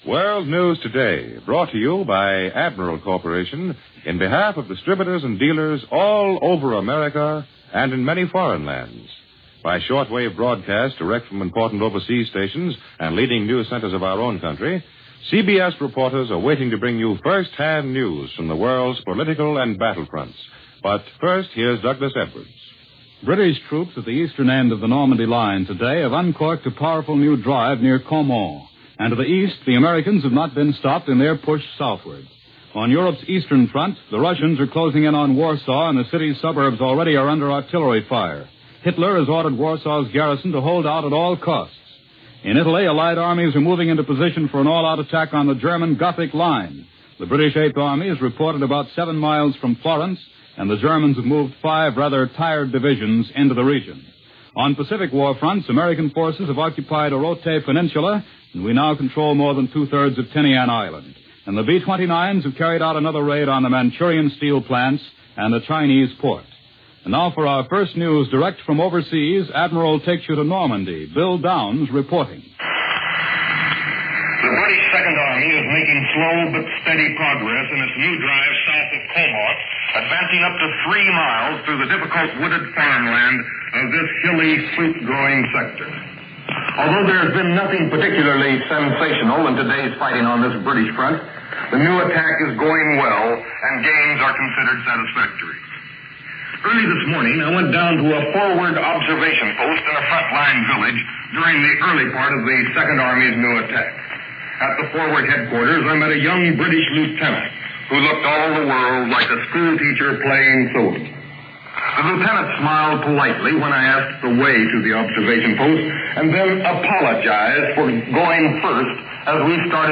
News for July 30, 1944